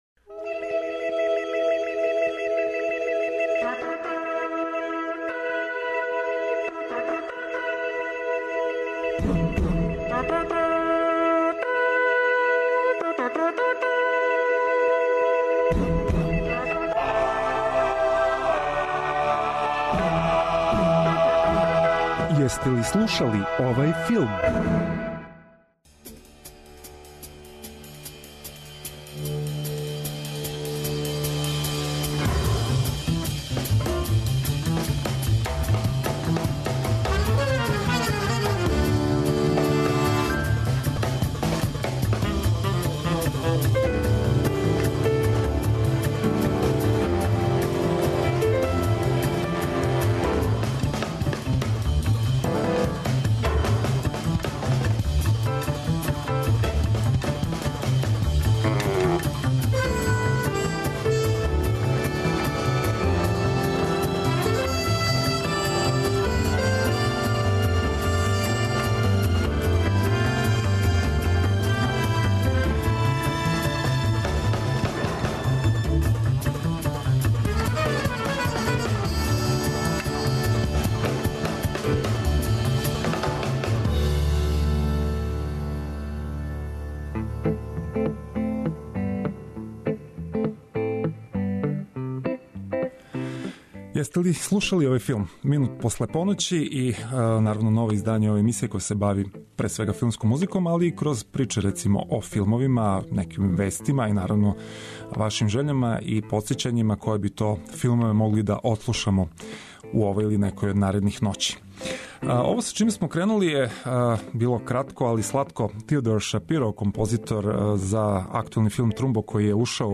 Autor: Београд 202 Филмска музика и филмске вести.